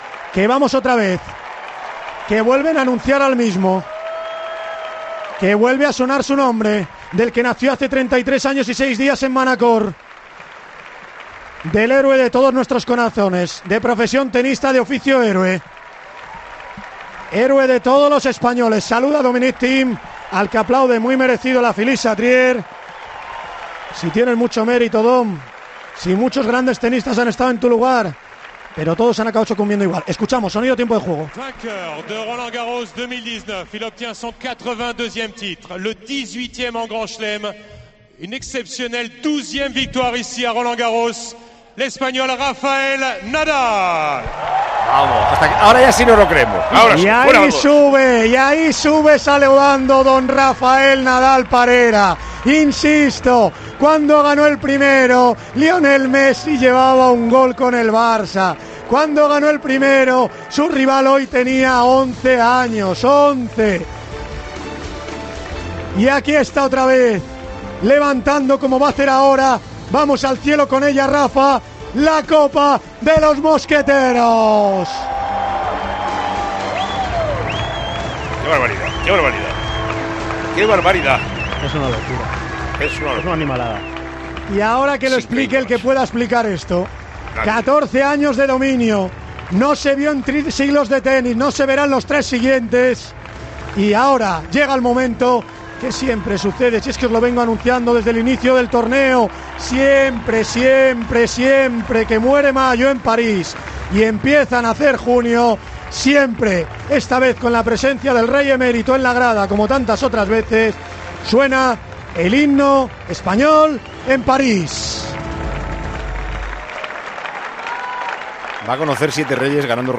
Así narramos en Tiempo de Juego la entrega del 12ª Roland Garros a Rafa Nadal.